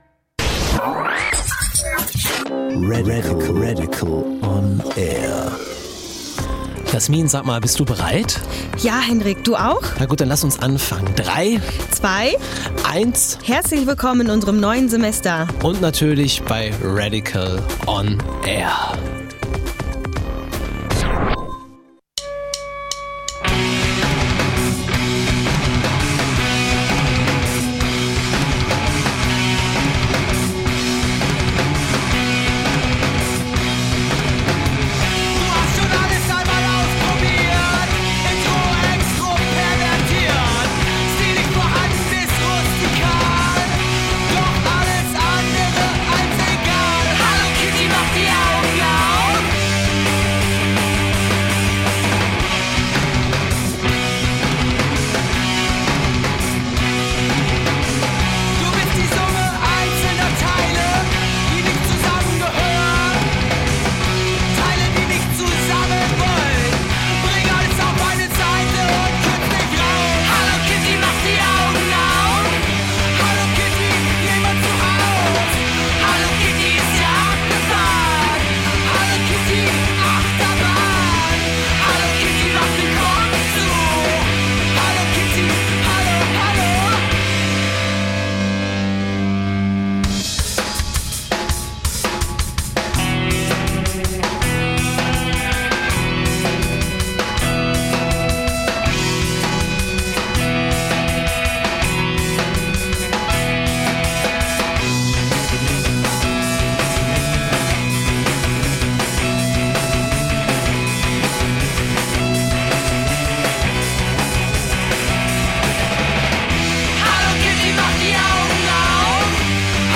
im Studio